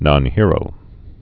(nŏn-hîrō)